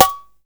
Snares
SNARE.43.NEPT.wav